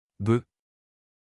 Son :
B-prononciation.mp3